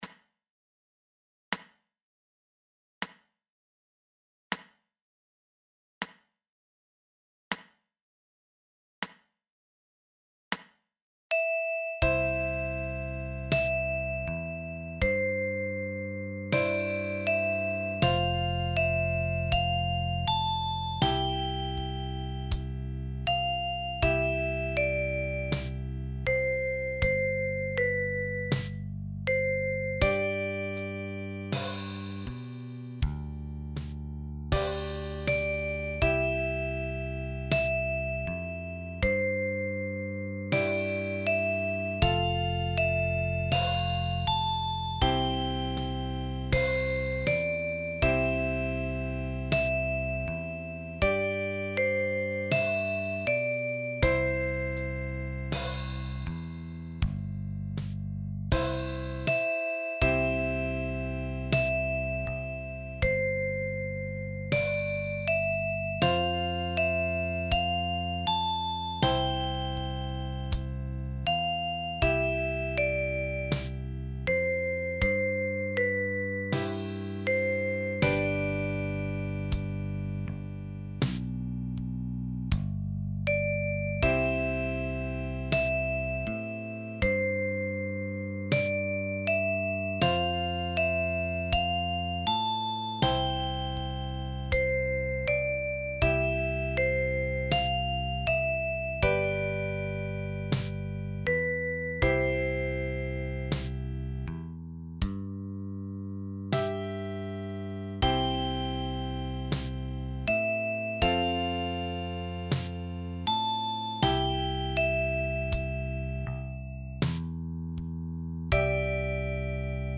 für die Kalimba mit 17 Stimmzungen.